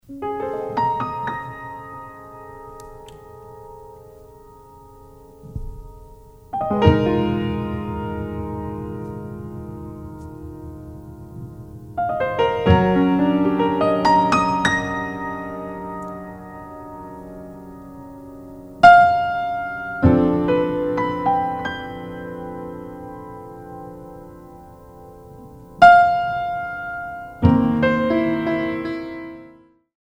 piano and percussion music